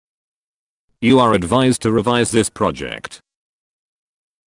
Q200. Write from dictation - PTE
You will hear a sentence.